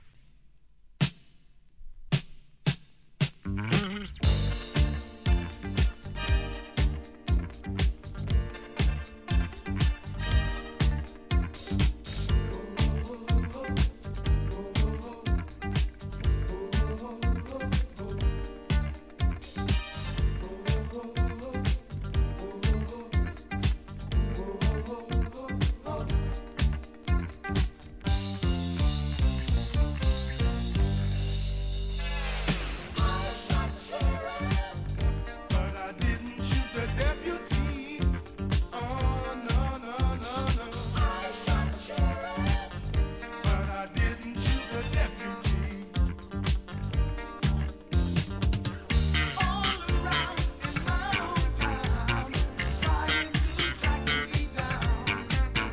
UK ACID JAZZ BAND